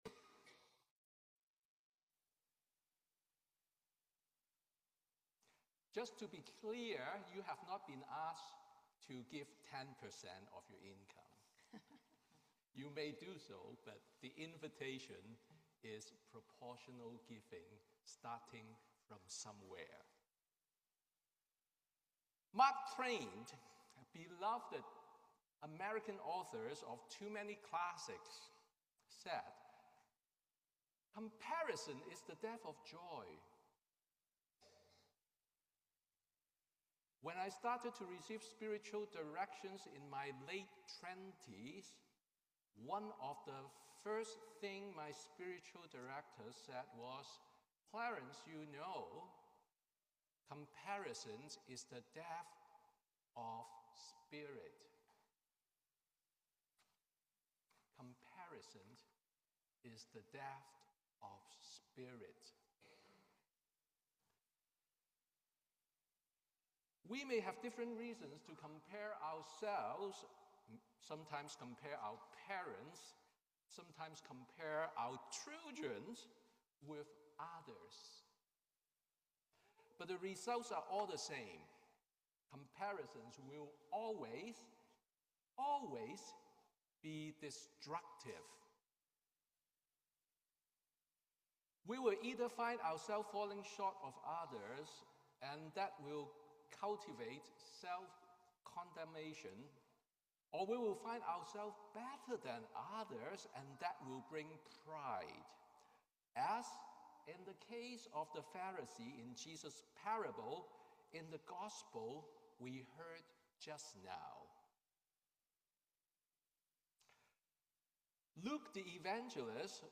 Sermon on the Twentieth Sunday after Pentecost